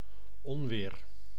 Ääntäminen
IPA: /ɔ.ʁaʒ/